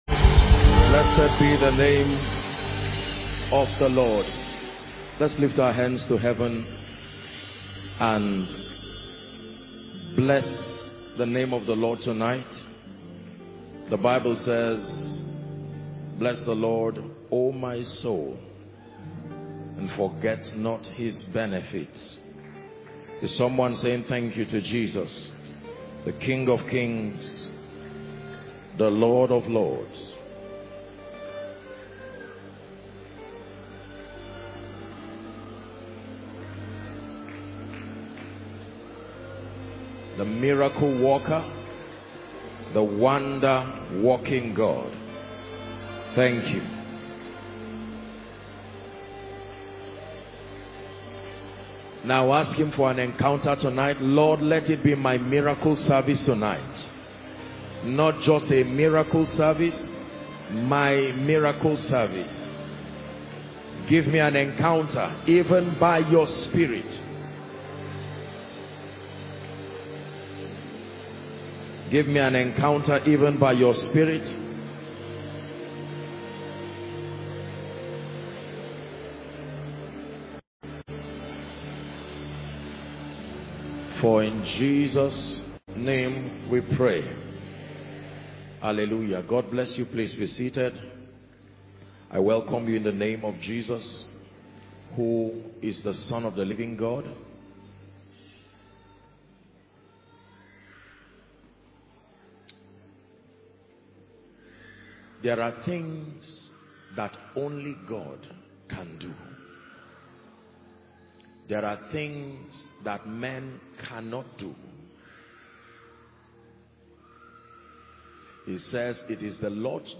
Download Mp3 June 2022 Miracle Service